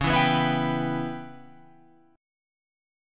Dbm7b5 chord